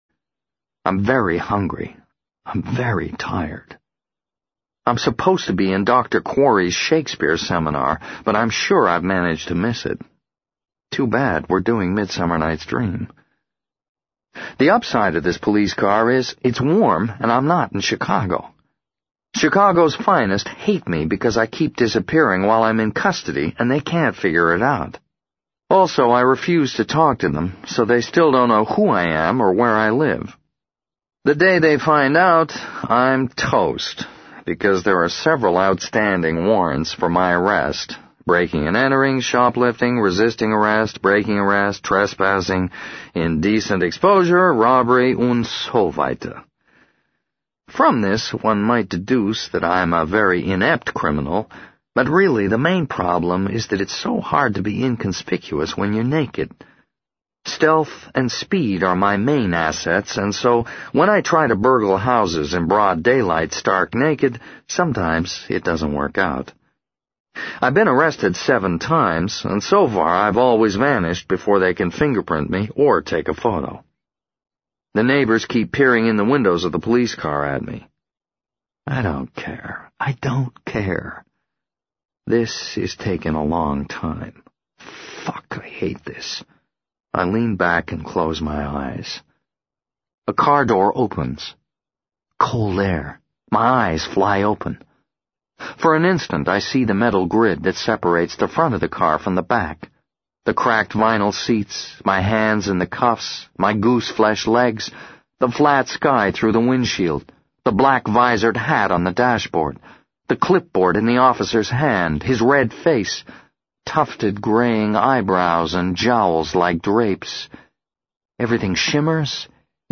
在线英语听力室【时间旅行者的妻子】51的听力文件下载,时间旅行者的妻子—双语有声读物—英语听力—听力教程—在线英语听力室